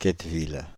Quetteville (French pronunciation: [kɛtvil]
Fr-Quetteville.ogg.mp3